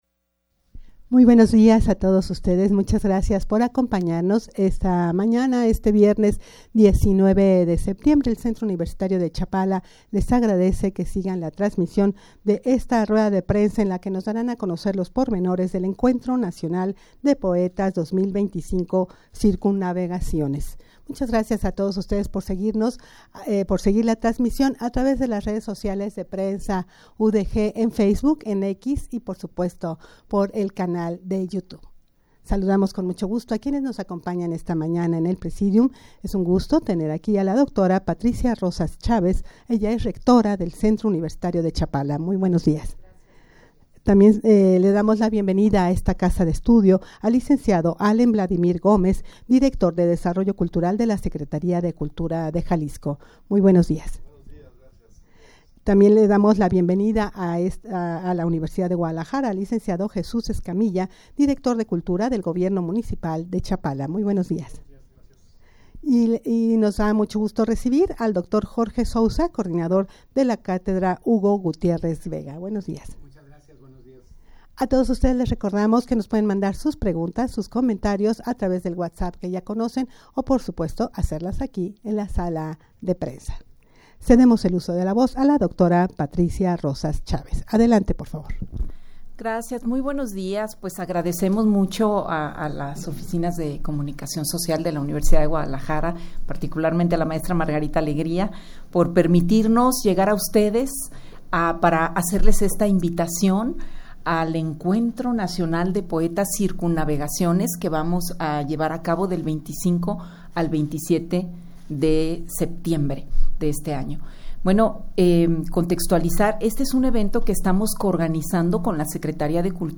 Audio de la Rueda de Prensa
rueda-de-prensa-para-dar-a-conocer-los-pormenores-del-encuentro-nacional-de-poetas-2025.mp3